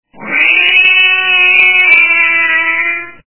» Звуки » Природа животные » Бешеный кот - М-я-я-у
При прослушивании Бешеный кот - М-я-я-у качество понижено и присутствуют гудки.
Звук Бешеный кот - М-я-я-у